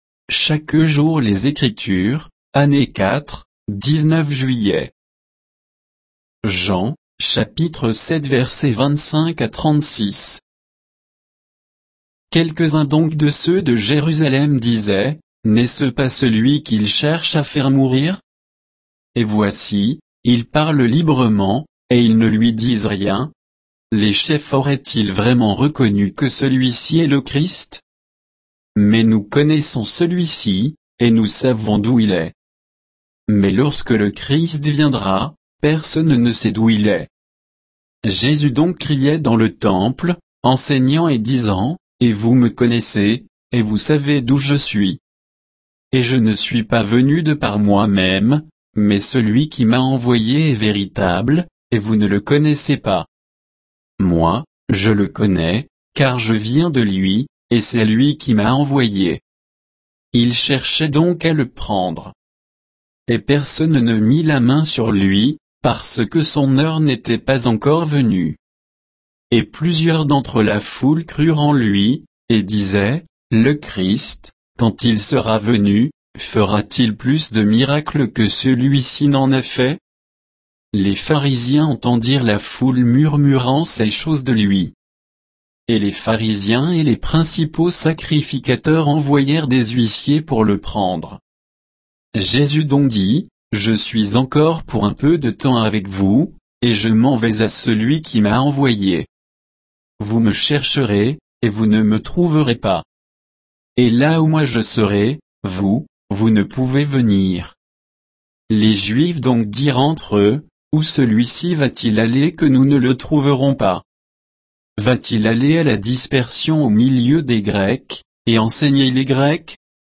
Méditation quoditienne de Chaque jour les Écritures sur Jean 7, 25 à 36